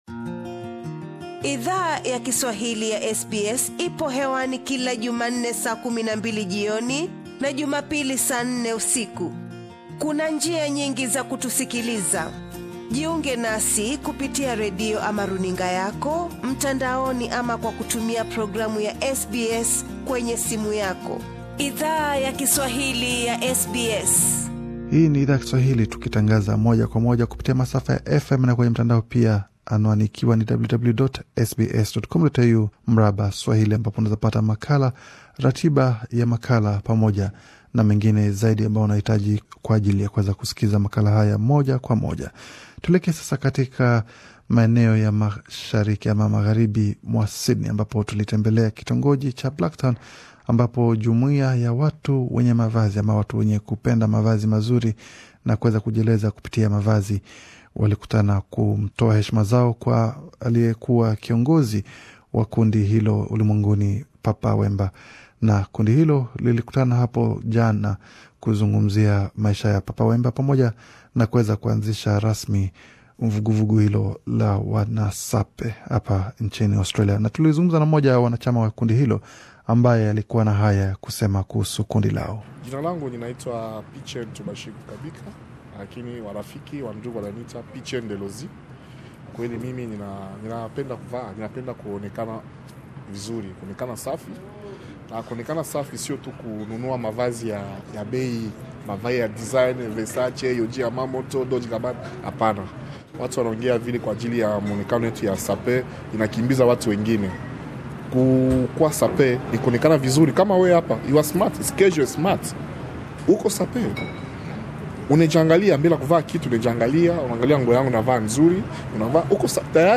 The Sapeurs movement has now arrived in Australia, and SBS Swahili accepted an invitation from it's members to attend it's tribute to the late Papa Wemba. Here are some of the conversations we had with the Sapeurs of Australia.